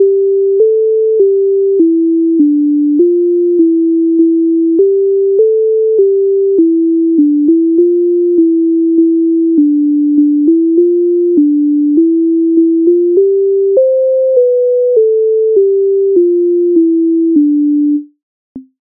MIDI файл завантажено в тональності C-dur
На городі біла глина Українська народна пісня зі збірки Михайловської
Ukrainska_narodna_pisnia_Na_horodi_bila_hlyna.mp3